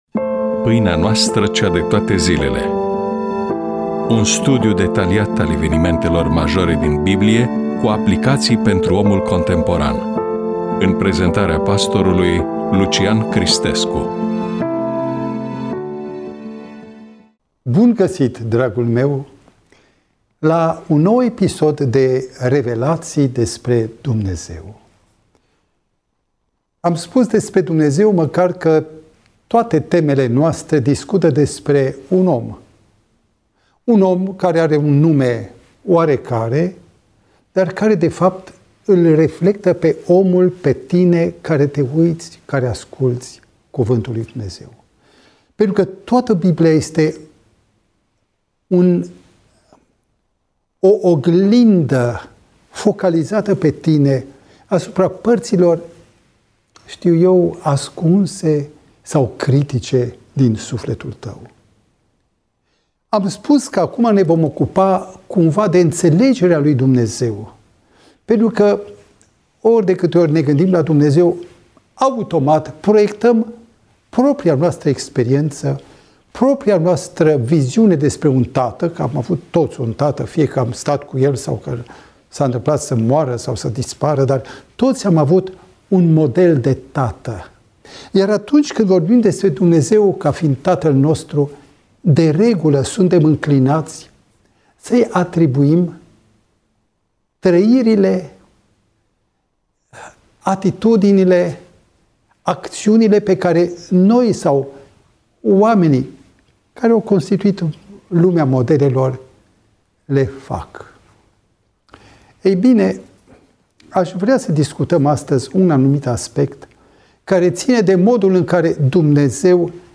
EMISIUNEA: Predică DATA INREGISTRARII: 10.04.2026 VIZUALIZARI: 4